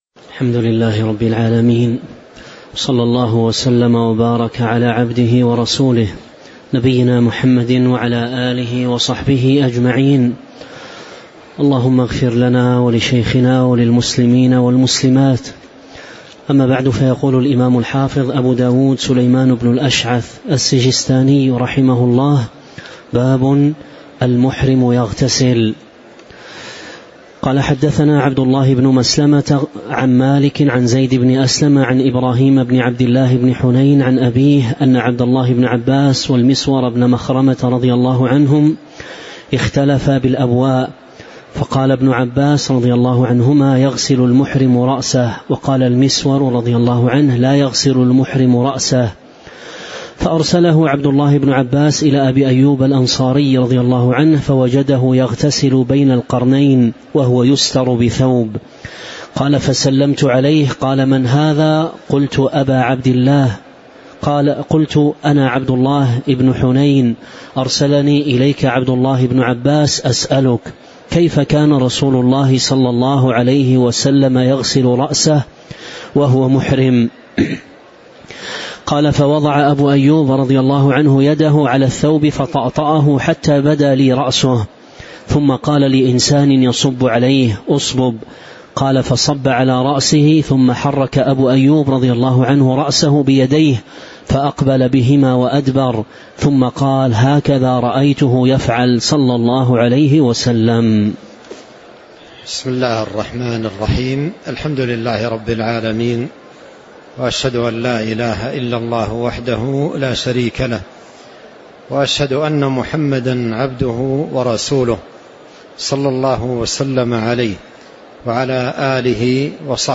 تاريخ النشر ٧ ذو الحجة ١٤٤٦ المكان: المسجد النبوي الشيخ